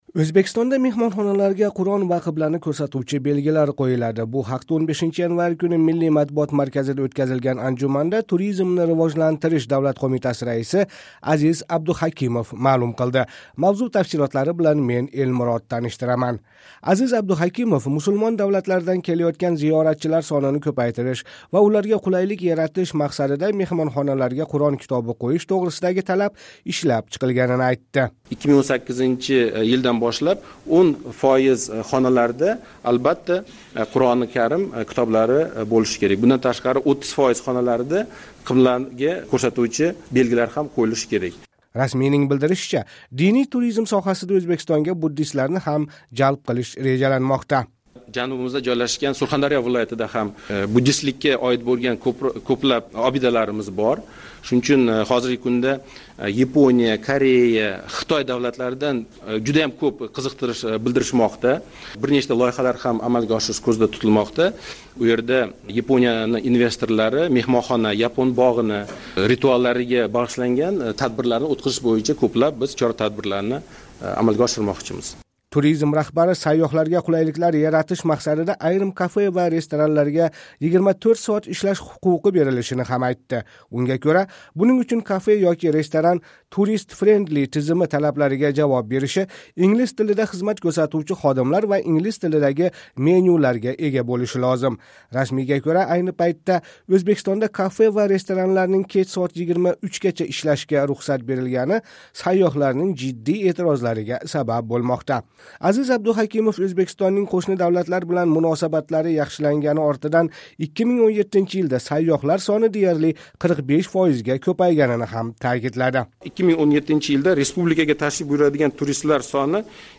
Бу ҳақда 15 январь куни Миллий матбуот марказида ўтказилган анжуманда Туризмни ривожлантириш давлат қўмитаси раиси Азиз Абдуҳакимов маълум қилди.